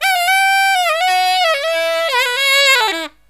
63SAXMD 03-R.wav